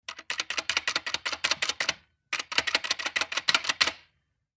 Old british desk telephone, tapping receiver rest to recall operator